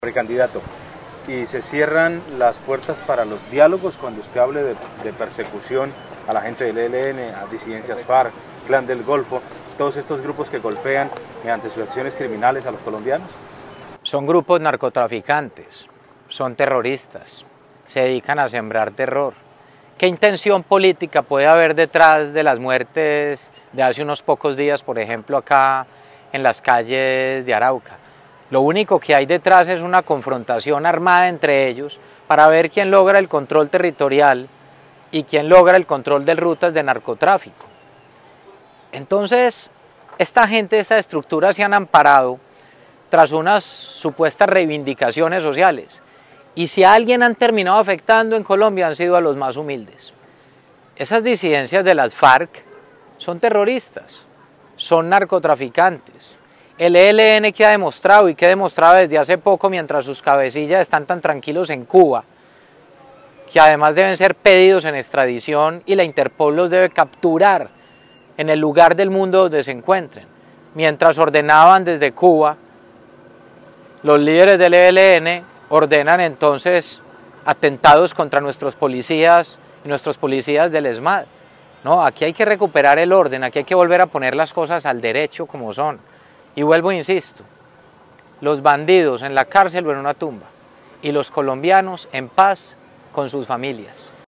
El ex Alcalde de Medellín, Federico Gutiérrez, hoy candidato presidencial visitó el municipio de Arauca donde entregó declaraciones a los periodistas de la región en pleno puente internacional José Antonio Páez, único paso fronterizo autorizado entre Colombia y Venezuela en este departamento.